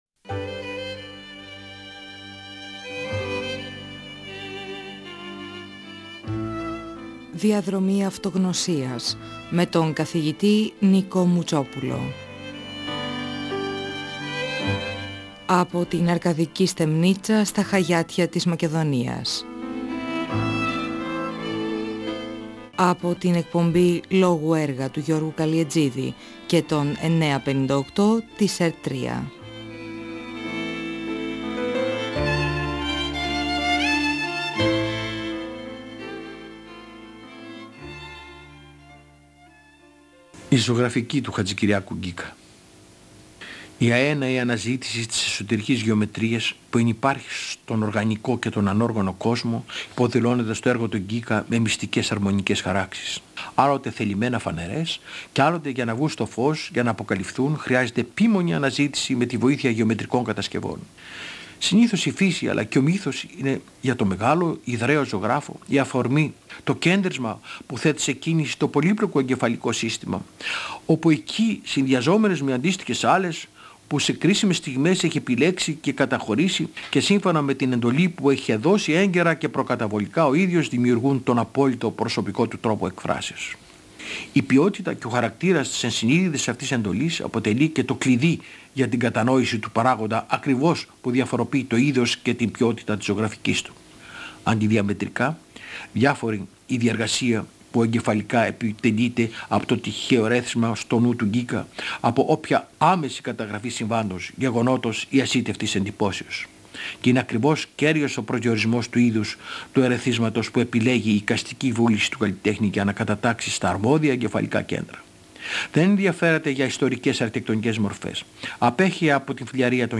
Ο αρχιτέκτονας Νικόλαος Μουτσόπουλος (1927–2019) μιλά για τη ζωγραφική τού (δασκάλου του) Νίκου Χατζηκυριάκου–Γκίκα: τις αρμονικές χαράξεις, τη φύση και τον μύθο που λειτουργούν σαν αφορμές, τον απόλυτα προσωπικό τρόπο έκφρασής του, την αφομοίωση της ελληνικής εικαστικής παράδοσης, την ποιότητα των χρωματικών επιλογών, τον συμβολισμό τών σχημάτων, τον αέναο πειραματισμό του.